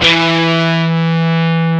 DIST GT1-E2.wav